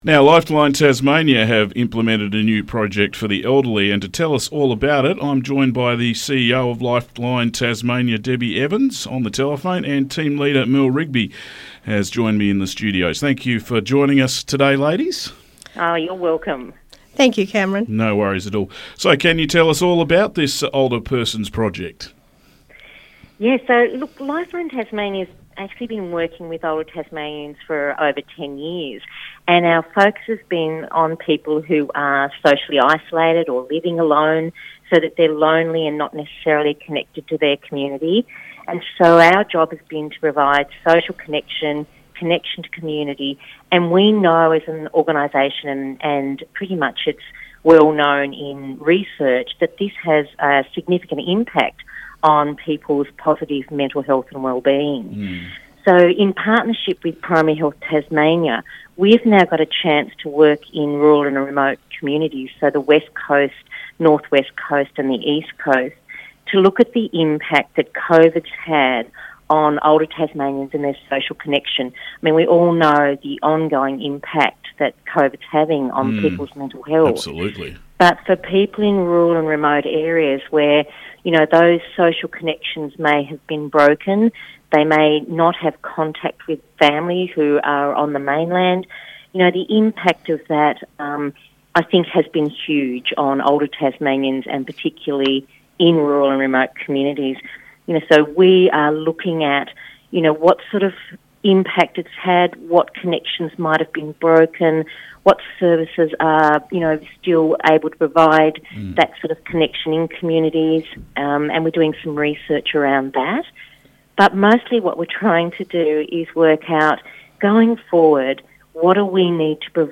Lifeline Interview